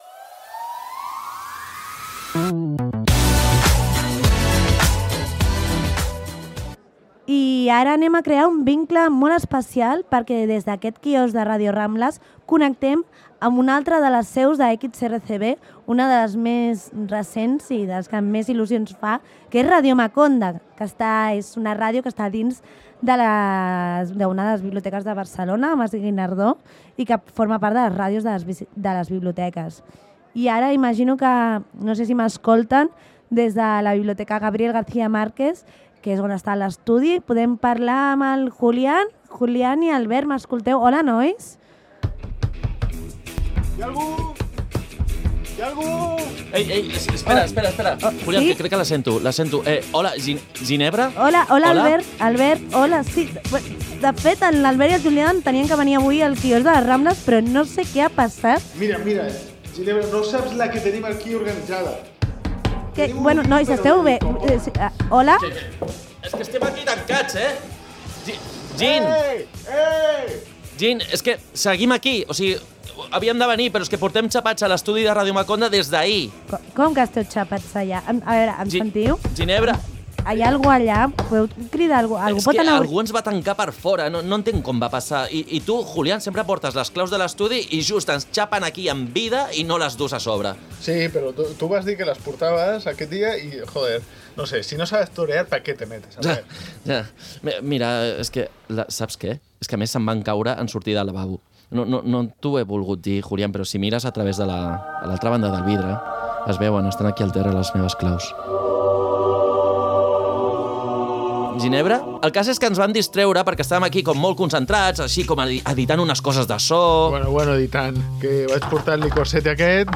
Masterització i cops de porta